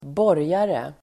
Uttal: [²b'år:jare]